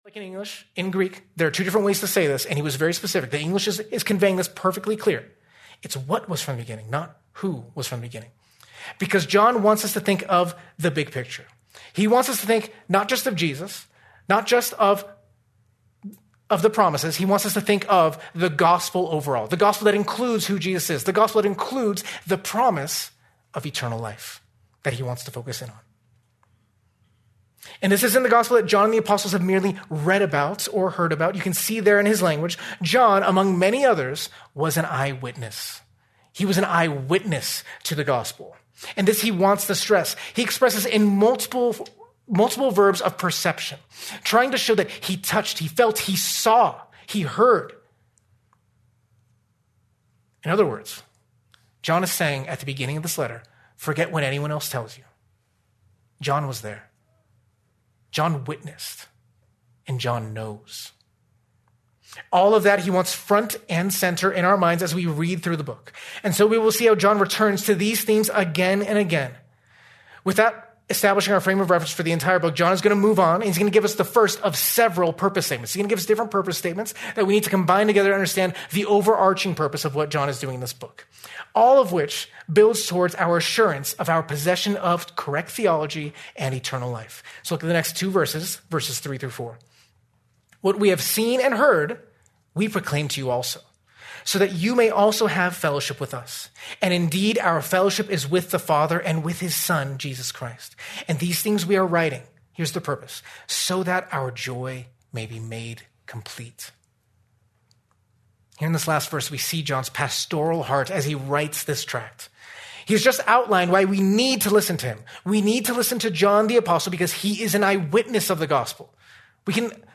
Due to technical difficulties, this sermon is incomplete